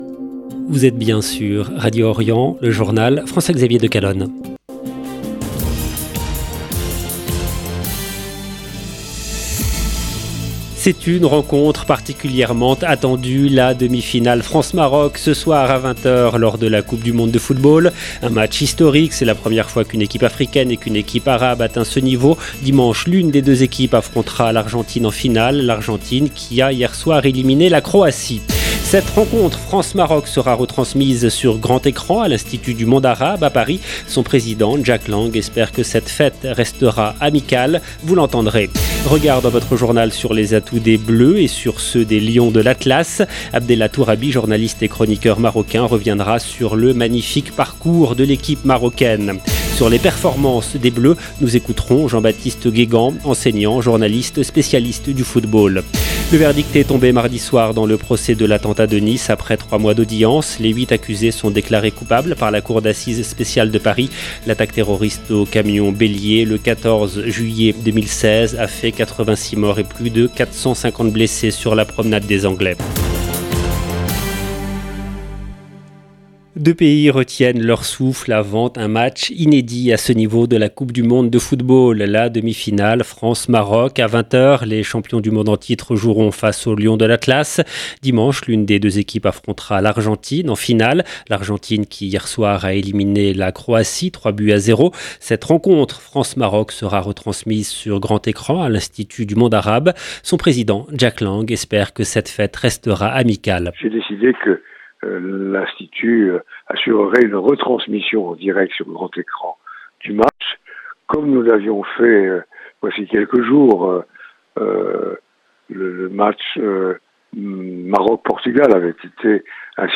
LE JOURNAL EN LANGUE FRANCAISE DU SOIR DU 14/12/22